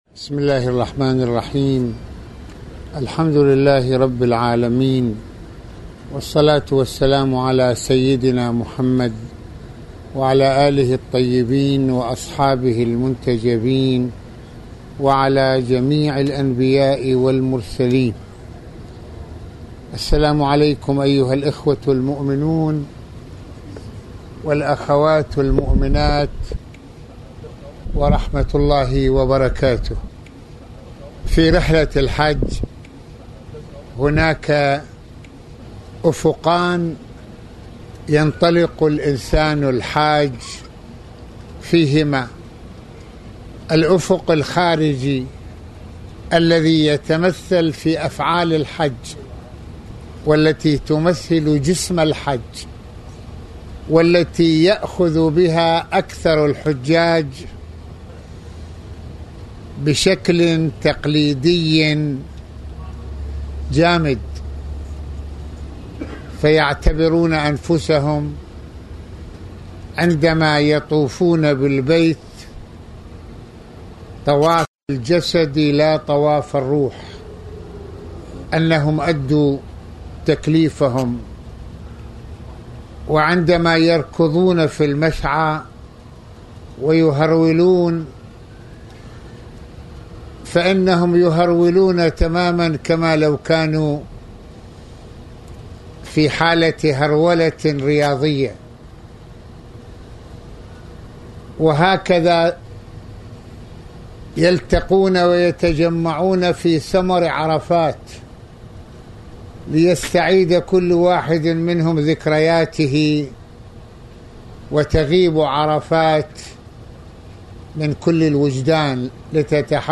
- يتحدّث العلامة المرجع السيّد محمّد حسين فضل الله (رض) في هذه المحاضرة عن أفق الحج في أفعاله من طواف وغير ذلك وهو ما يفعله الناس بشكل تقليدي جسدي، وأفق الحج الإنساني الداخلي المتمثل بحج العقل والقلب إلى بارئهما وحج المسير إلى الله في مواجهة كل التحديات التي تحاول إسقاط الإنسان...